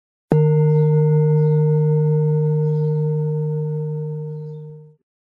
文件:钟声.mp3
钟声.mp3.ogg